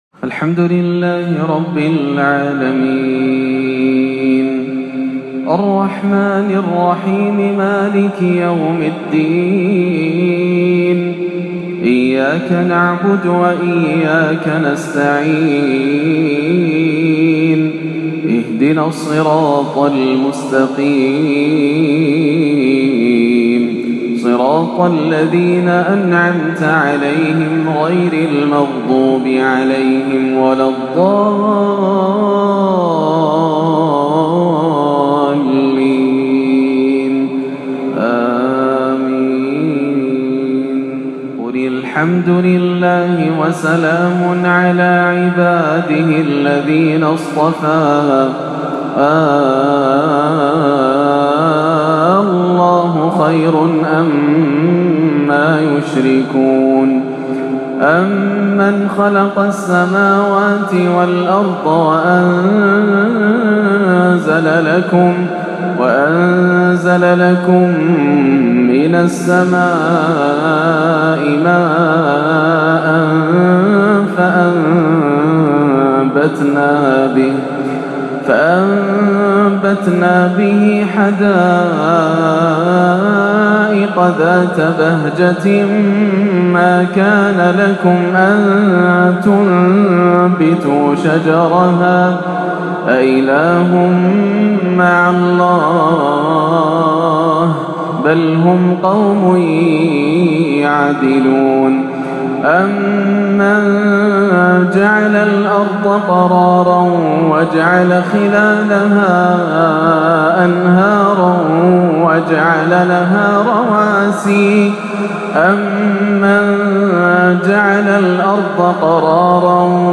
" أمن يجيب المضطر إذا دعاه " عشائية بأسلوب جميل وخاشع - الأربعاء 7-8-1438 > عام 1438 > الفروض - تلاوات ياسر الدوسري